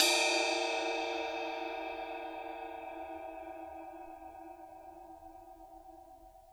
susCymb1-hitstick_pp_rr1.wav